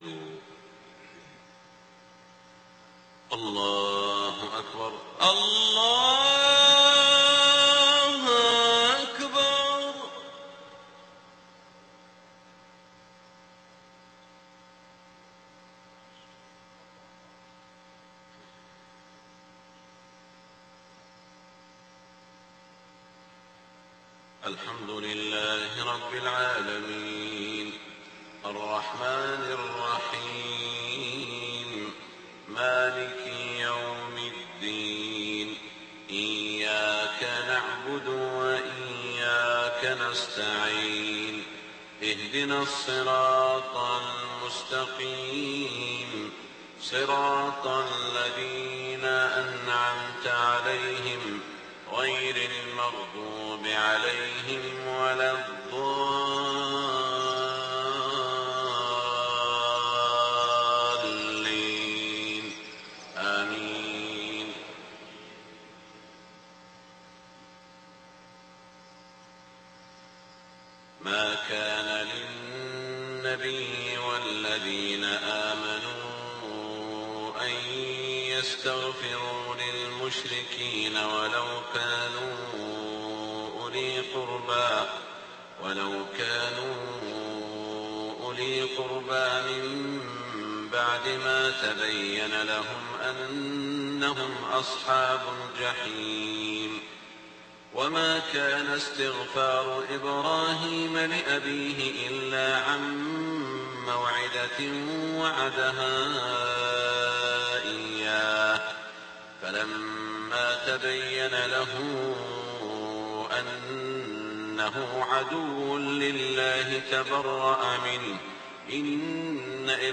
صلاة الفجر 7-3-1428هـ خواتيم سورة التوبة 113-129 > 1428 🕋 > الفروض - تلاوات الحرمين